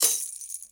Closed Hats
Boom-Bap Hat CL 69.wav